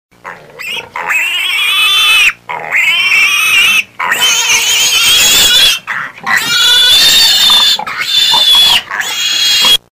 Звуки свиней
На этой странице собраны разнообразные звуки, которые издают свиньи: от забавного хрюканья до громкого визга.